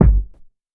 • 90's Warm Sounding Rap Kick G# Key 645.wav
Royality free kick drum single hit tuned to the G# note. Loudest frequency: 138Hz
90s-warm-sounding-rap-kick-g-sharp-key-645-LGd.wav